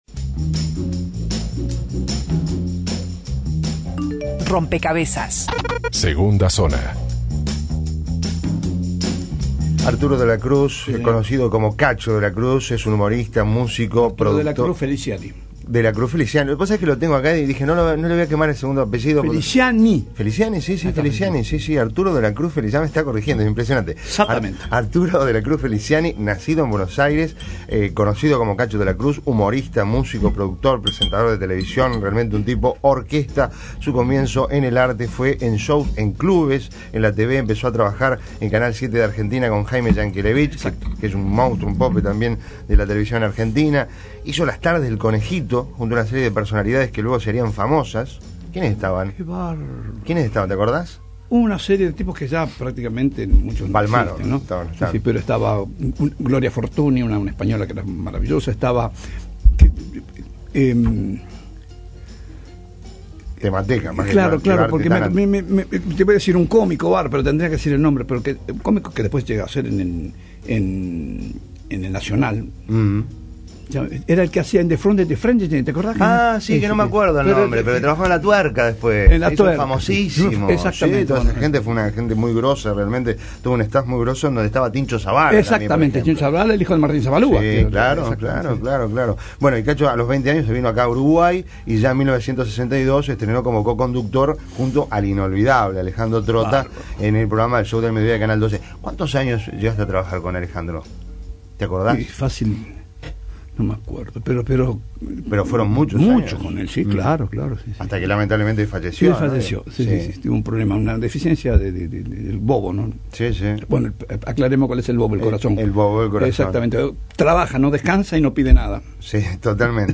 ENTREVISTA Un Cacho de Bochinche en Rompkbzas Imprimir A- A A+ Músico, compositor, actor, conductor, carnavalero, humorista, guionista y sobre todo hombre de televisión.